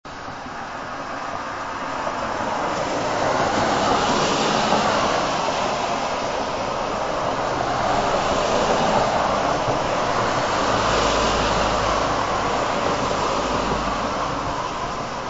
Road Trip in the Rain
Note that the samples are mp3 (lower quality than the CD) to keep download times short.
As the rain falls, the stream of travelers continues, charging through torrents of water, spraying clouds of mist in all directions. In the ebb and flow of rain and travelers, the sound of engines purring and rubber cutting through water is almost hypnotic.
off-white-noise-road-trip-in-rain-15s.mp3